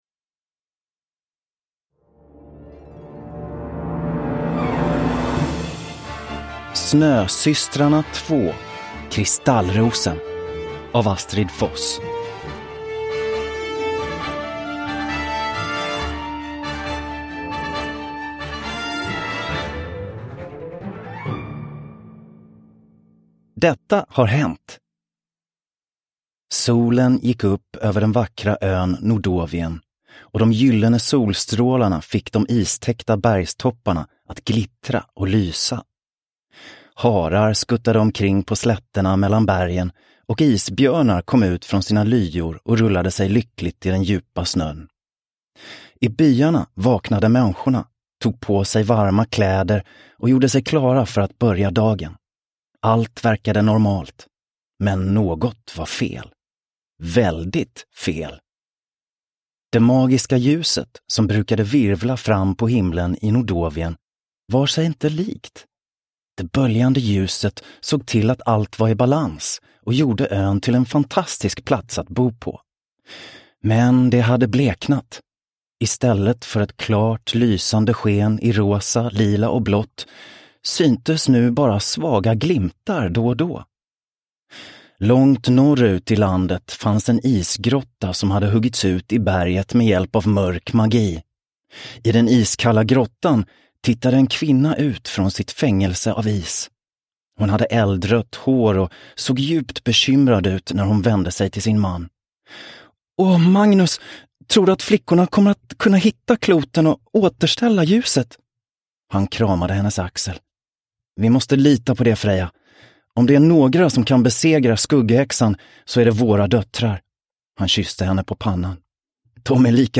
Kristallrosen – Ljudbok – Laddas ner